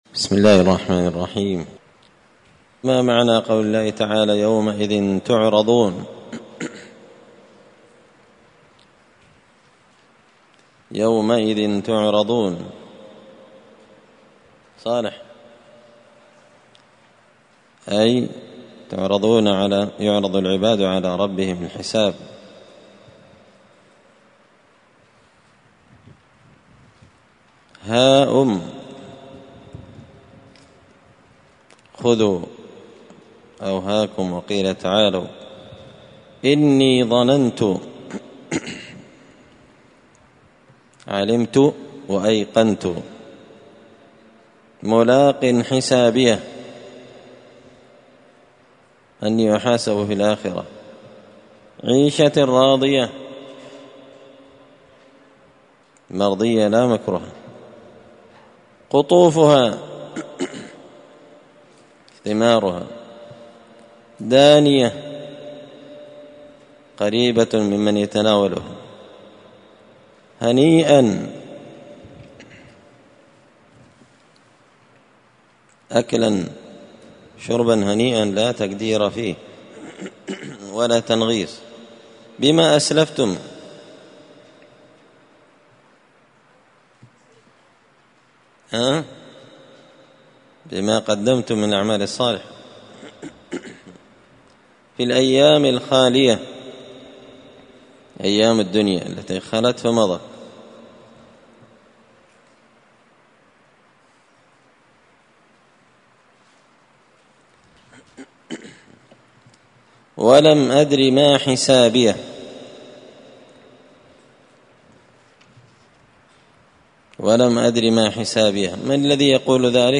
زبدة الأقوال في غريب كلام المتعال الدرس الثاني والسبعون (72)